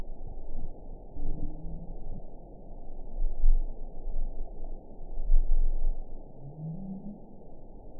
event 910251 date 01/17/22 time 16:20:51 GMT (3 years, 5 months ago) score 8.37 location TSS-AB07 detected by nrw target species NRW annotations +NRW Spectrogram: Frequency (kHz) vs. Time (s) audio not available .wav